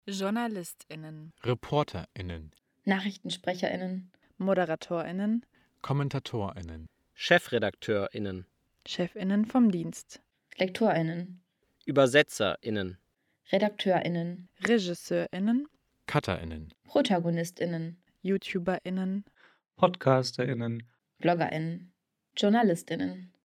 Wie klingt Gendern?
So geht sprechen mit Lücke
Sprechprobe-Glottisschlag.mp3